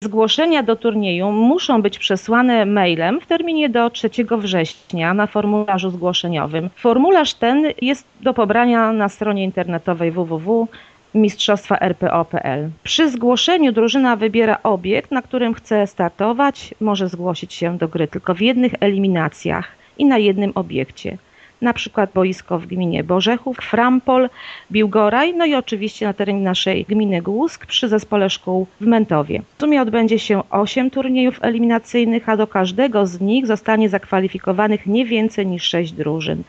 - Lista zespołów, które wystąpią w rozgrywkach, nie jest jeszcze zamknięta - mówi zastępca wójta Gminy Głusk Urszula Paździor: